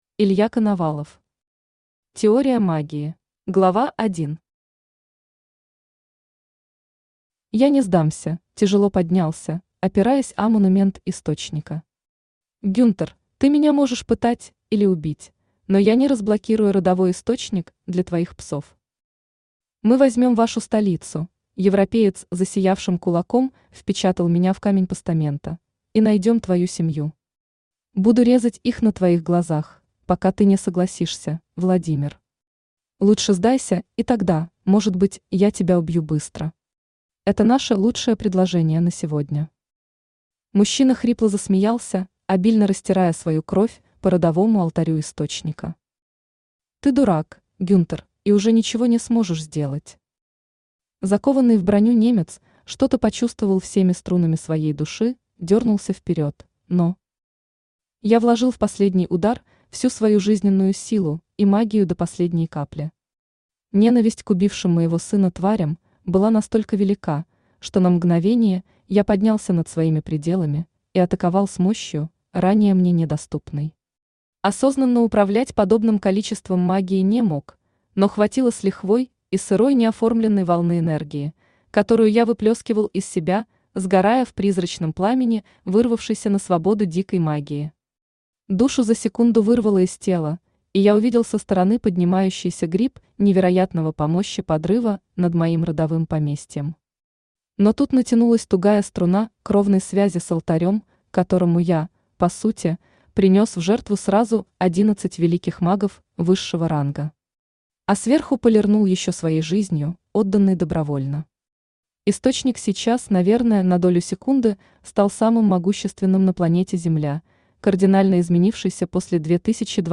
Aудиокнига Теория магии Автор Илья Коновалов Читает аудиокнигу Авточтец ЛитРес.